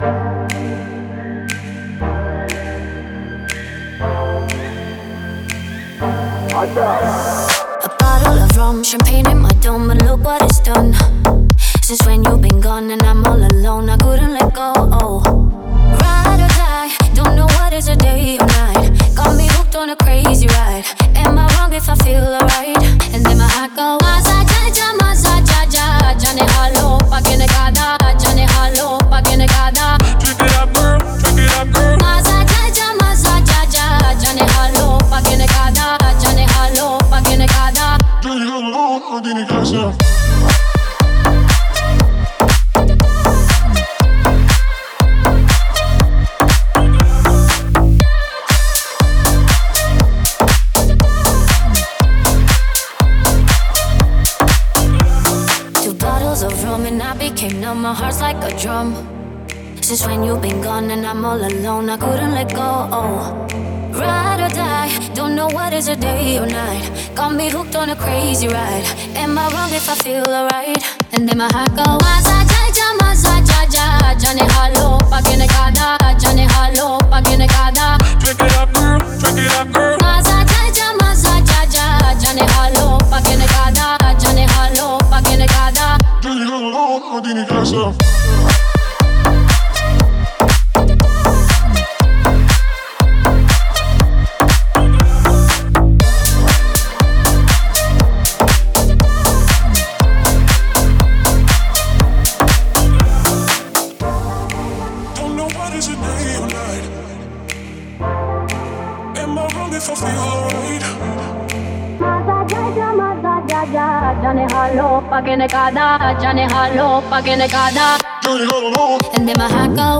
Трек выделяется динамичным звучанием